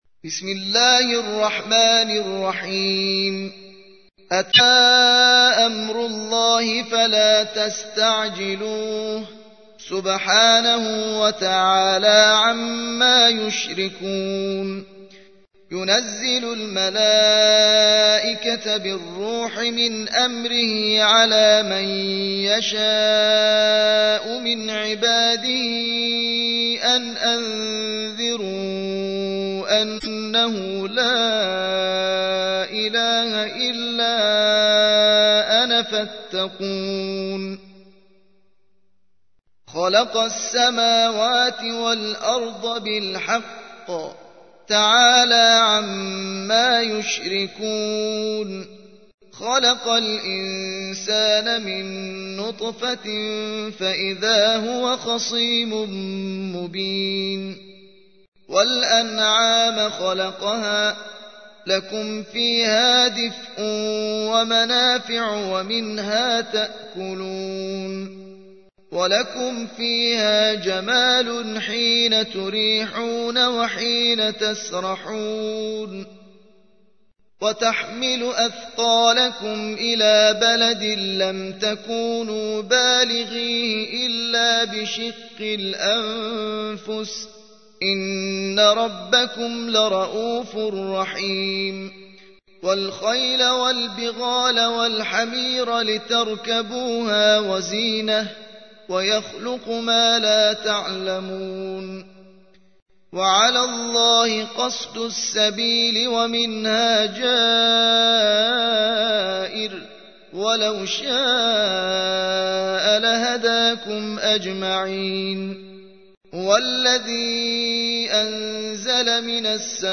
16. سورة النحل / القارئ